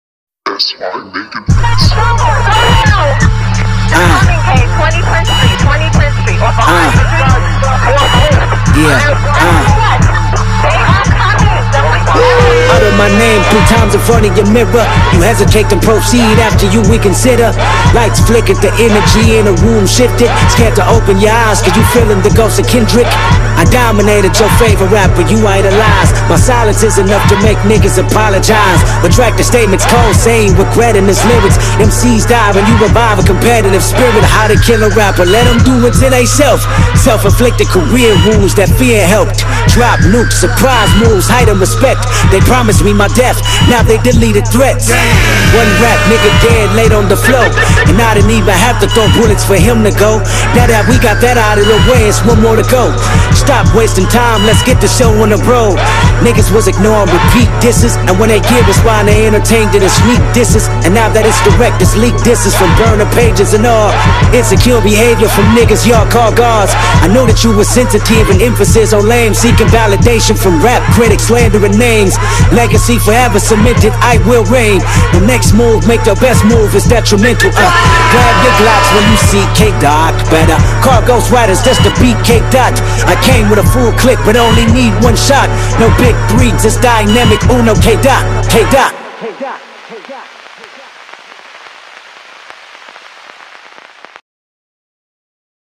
دانلود آهنگ سبک هیپ هاپ